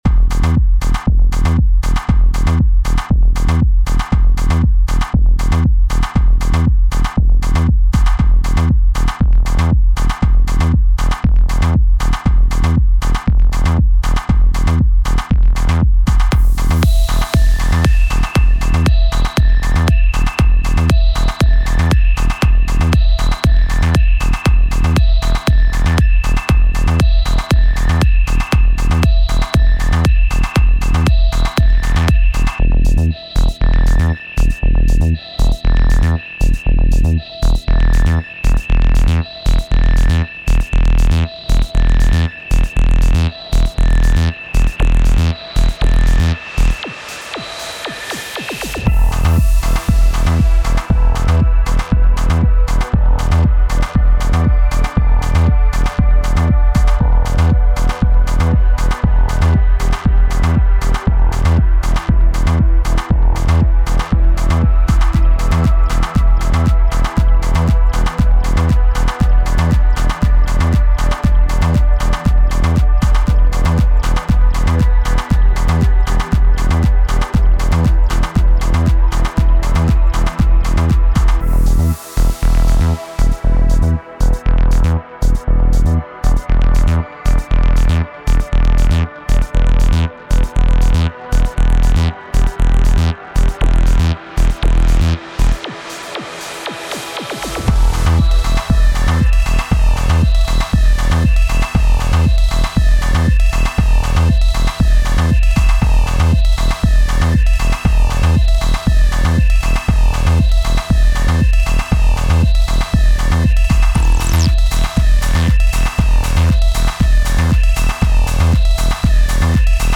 Deephouse, Happy Hardcore und Hardtech